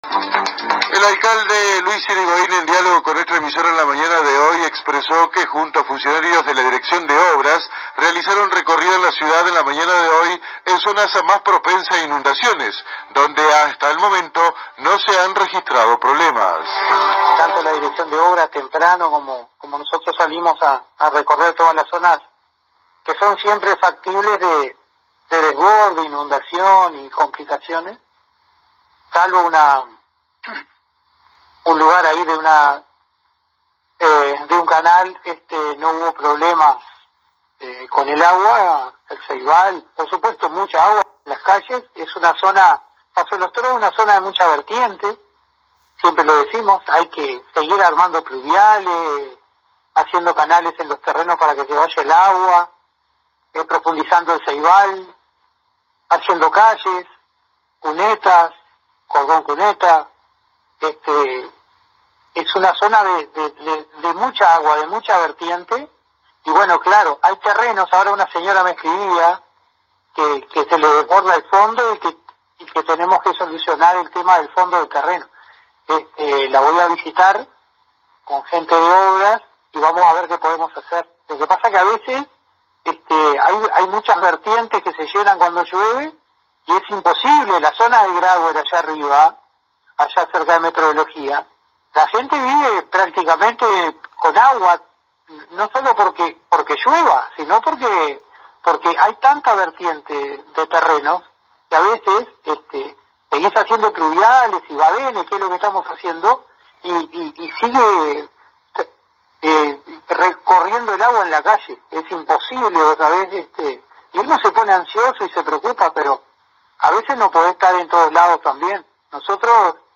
Así lo informó el Alcalde Luis Irigoin, consultado por la AM 1110 local sobre si hubo alguna afectación por las lluvias en la ciudad, a lo que contestó que a pesar de que Paso de los Toros es una zona de muchas vertientes, no se había presentado ninguna calamidad en el transcurrir del día.
Escuche a Luis Irigoin aquí: